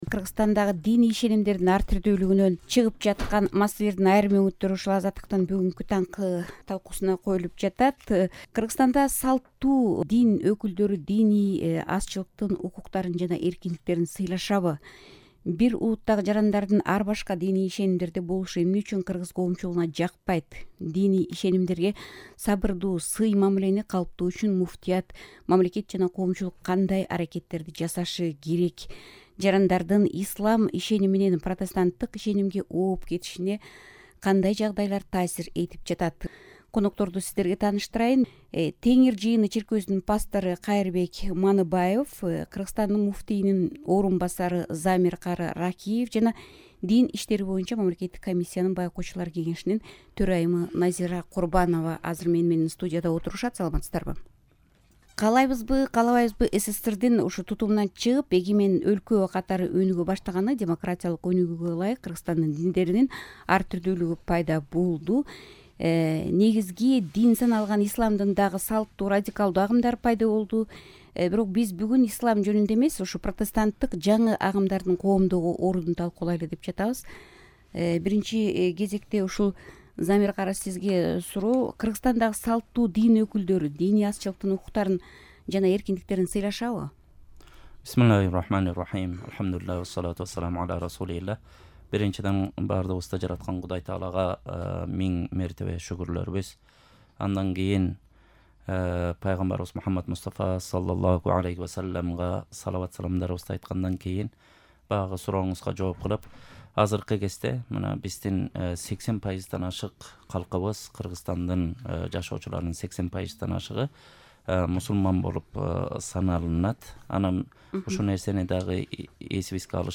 талкуу курду.